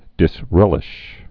(dĭs-rĕlĭsh)